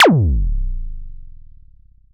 Synth Fx Stab 01.wav